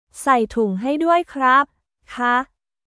サイ トゥン ハイ ドゥアイ クラップ／カー